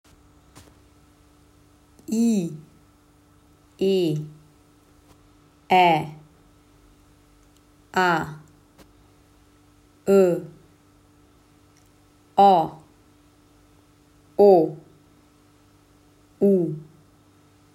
Listen to the vowels
(i, e, ɛ, a, ə, ɔ, o, u)